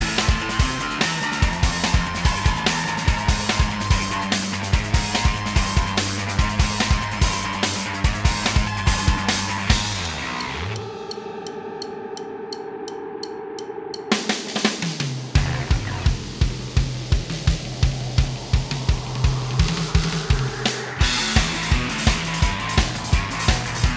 Minus All Guitars Indie / Alternative 3:15 Buy £1.50